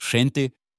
Soft G (before E, I) = "J" as in "judge"
people JEN-teh